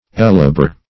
Ellebore \El"le*bore\